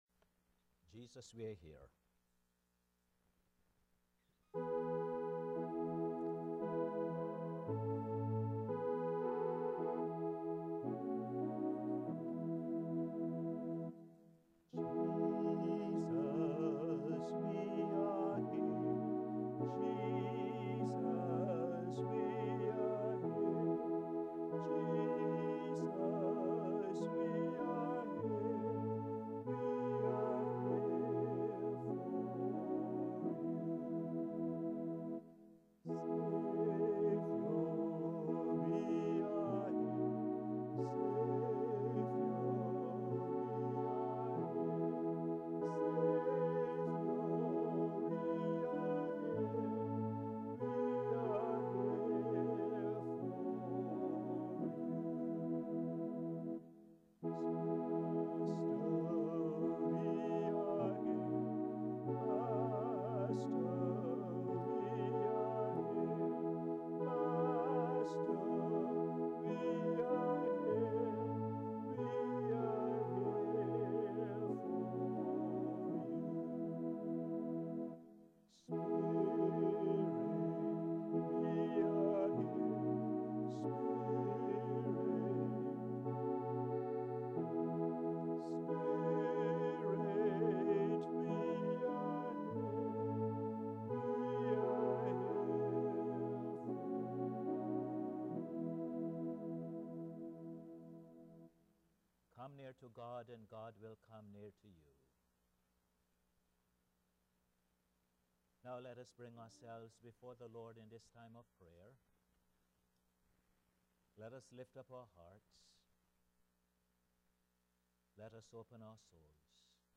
Posted in Sermons on 17.